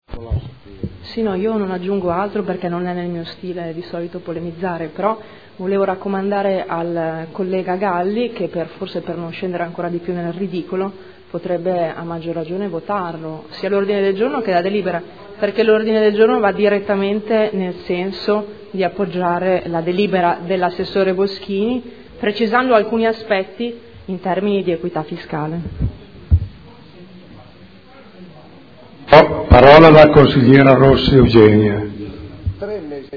Giuliana Urbelli — Sito Audio Consiglio Comunale